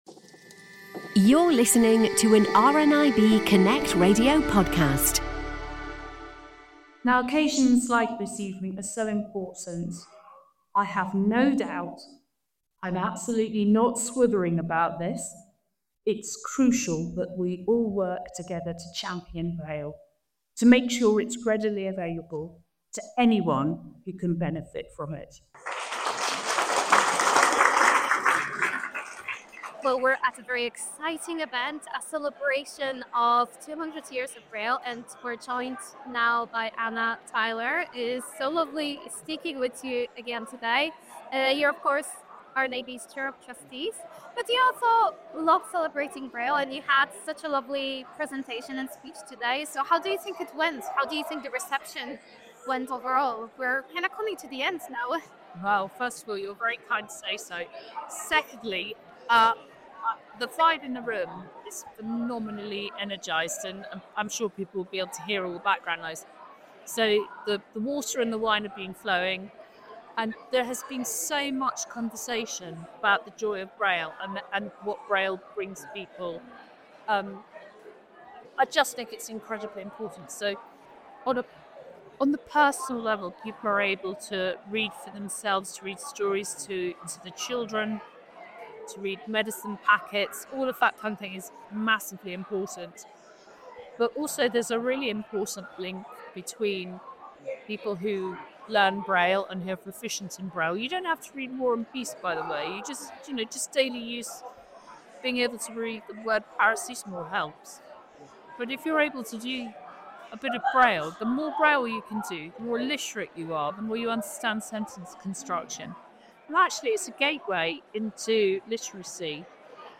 On Wednesday 29th of January RNIB Scotland's Braille 200 reception was held at the Scottish Parliament.